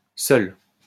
wymowa:
IPA[sœl] ?/i